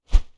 Close Combat Swing Sound 15.wav